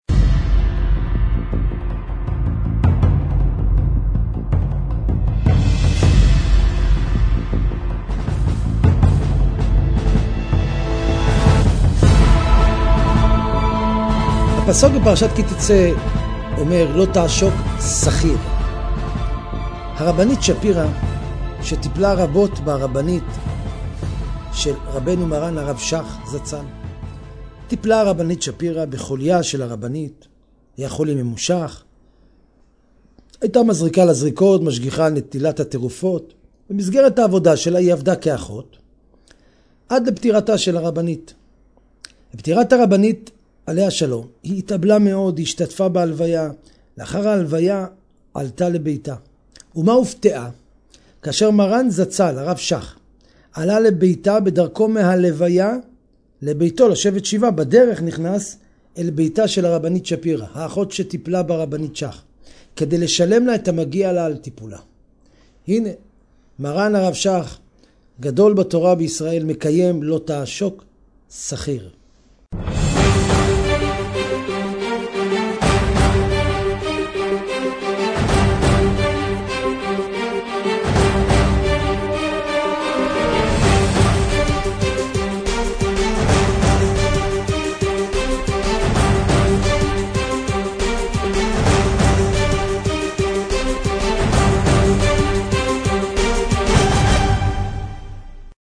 torah lesson